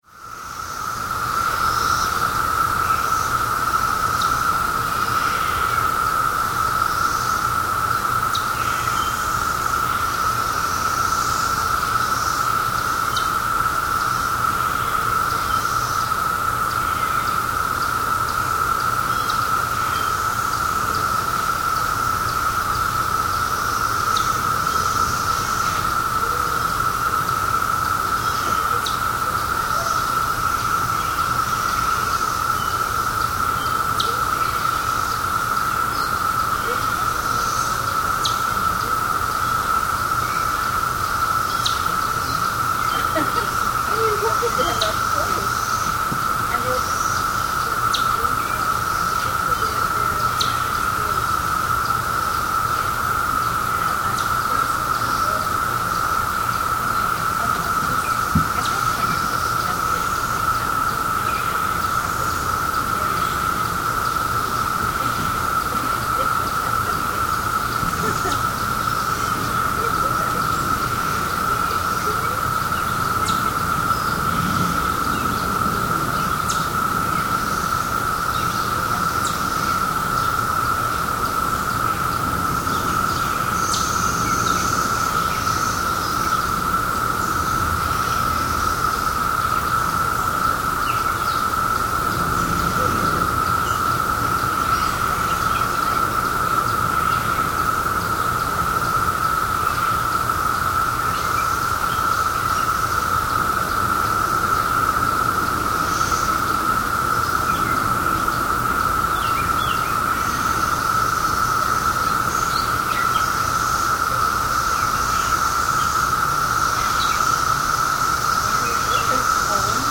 Excerpts of the 17-year Cicada Brood XIII from Glenview, IL (2007)
(N.B. Several factors influenced the intensity of male chirping. The total population of cicadas peaked in mid-June. Activity increased with higher daily temperatures and rising sun, while precipitation along with cooler air brought about a decrease or absence of chirping.)